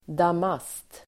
Ladda ner uttalet
damast substantiv, damask Uttal: [dam'as:t] Böjningar: damasten, damaster Definition: enfärgad vävnad med rikt invävt mönster (unicoloured, intricate patterns in a satin weave) Sammansättningar: damast|duk (damask cloth)